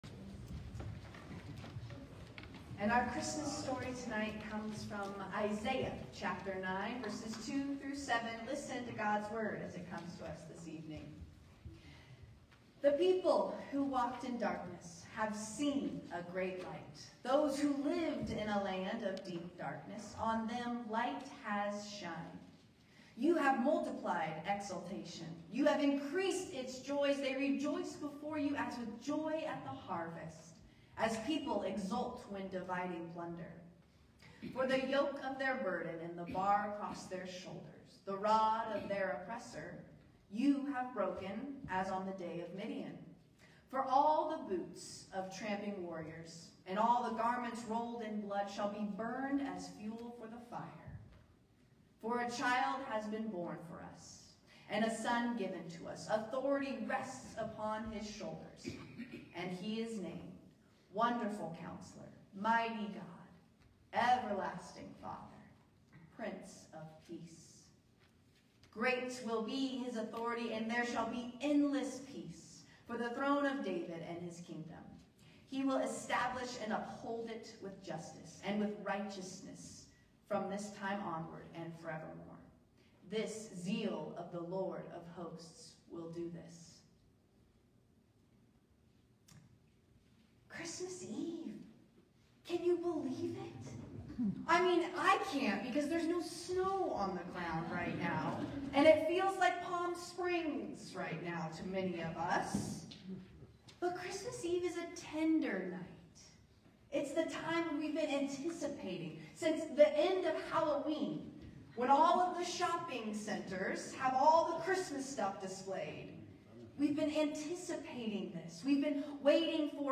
12/24/25 Sermon: What Can't Wait